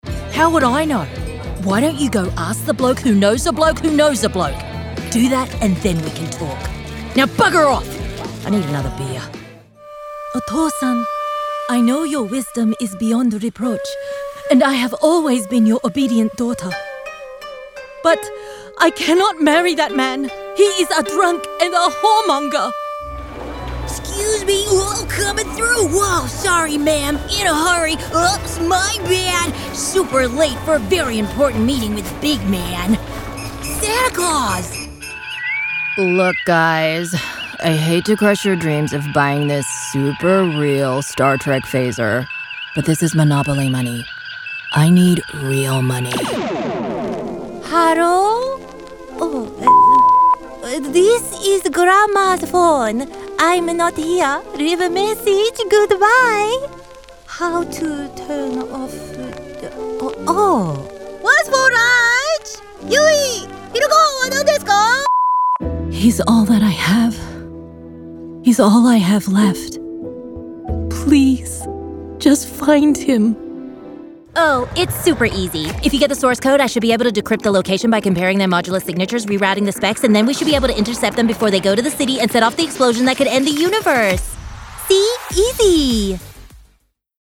Teenager, Young Adult, Adult
australian | character
standard us | natural
ANIMATION 🎬